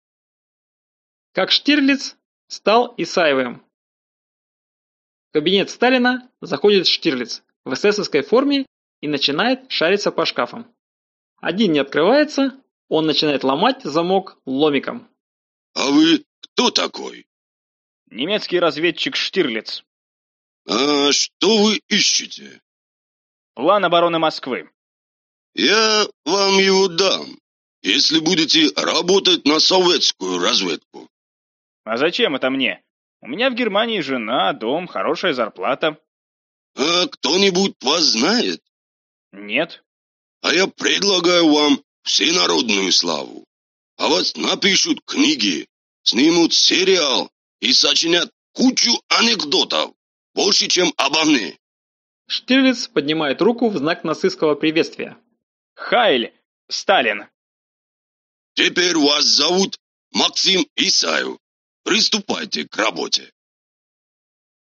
Аудиокнига 100 новых смешных сценок. выпуск 2 | Библиотека аудиокниг